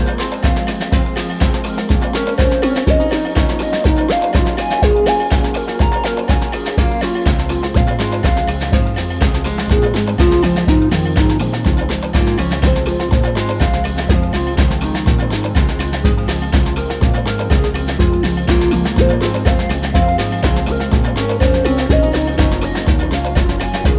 Live Arrangement Down Two Pop (1980s) 5:46 Buy £1.50